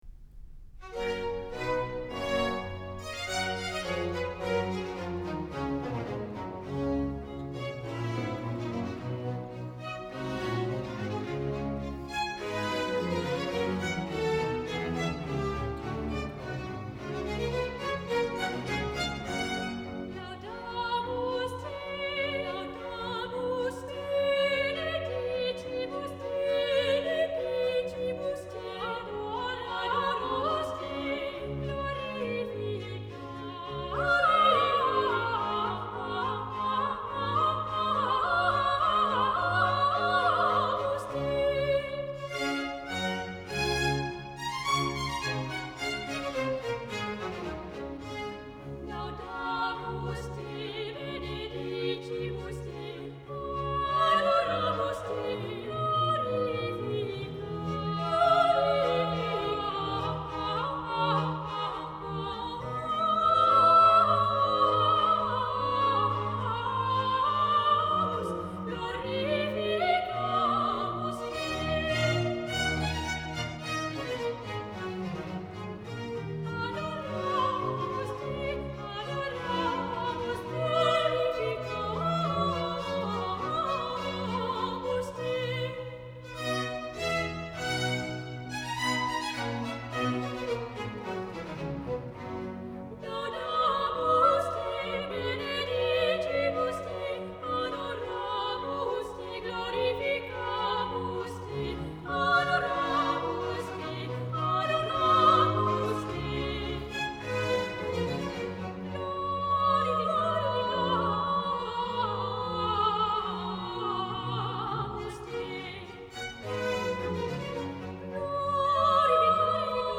2 sopranos